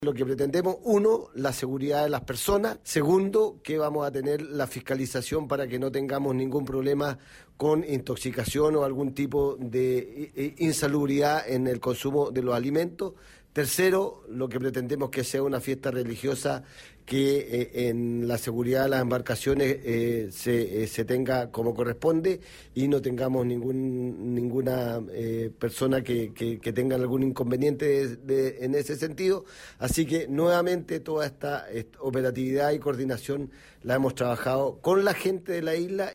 A su vez, la autoridad manifestó que habrá inspecciones al expendio de alimentos para que no se produzcan intoxicaciones, entre otras supervisiones que habrá en el lugar, destacando también el comercio ambulante.